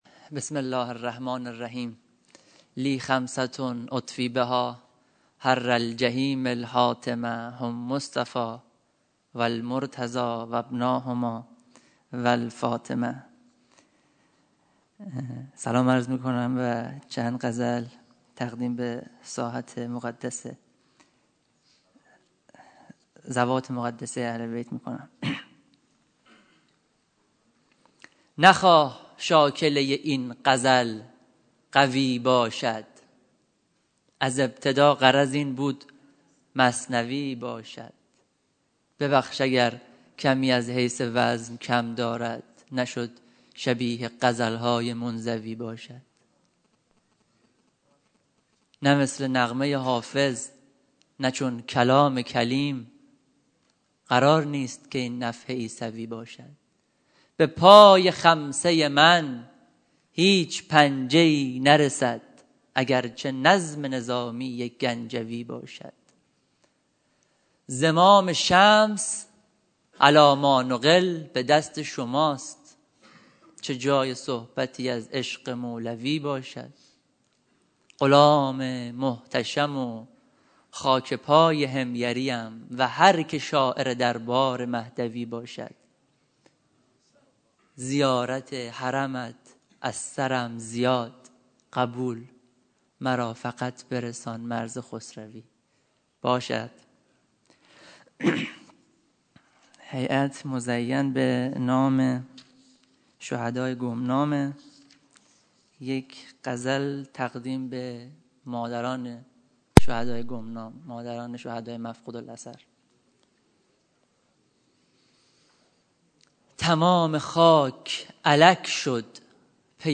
پنجشنبه شب در حسینیه شهدای قهرود + گزارش تصویری و صوت
صوت شعر خوانی